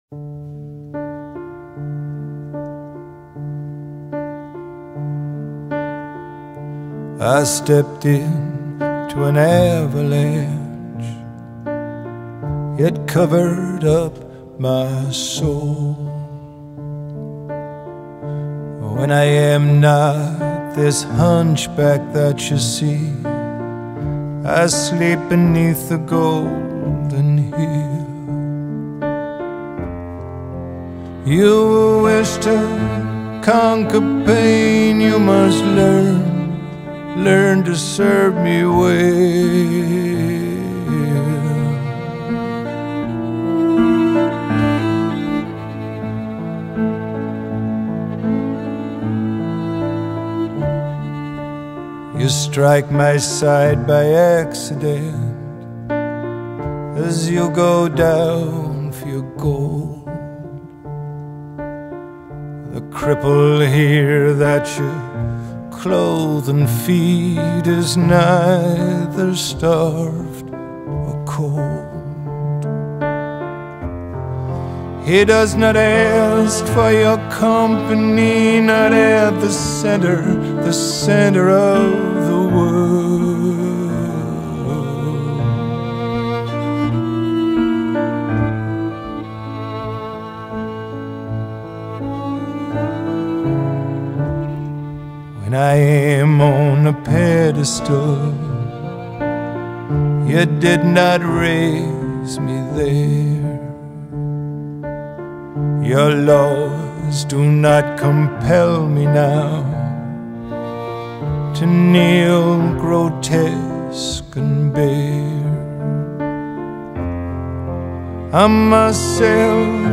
Post-Punk, Alternative Rock